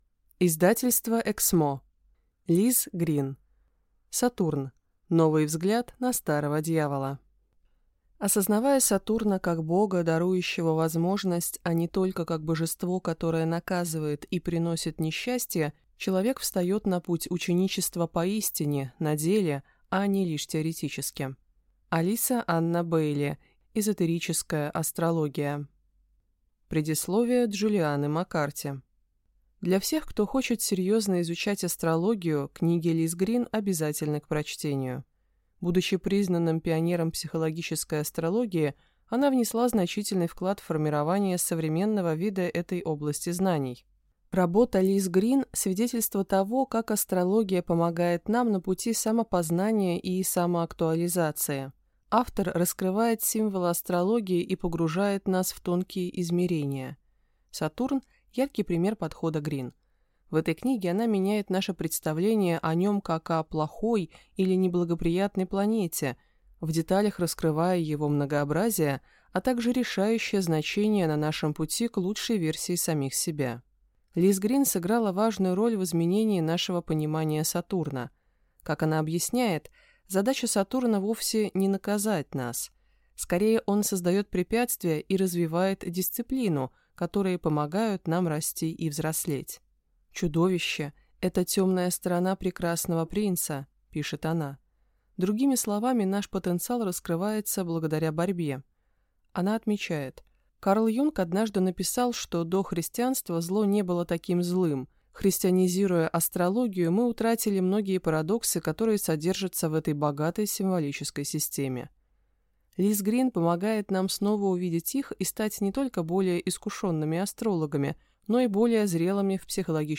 Аудиокнига Сатурн. Новый взгляд на старого дьявола | Библиотека аудиокниг
Прослушать и бесплатно скачать фрагмент аудиокниги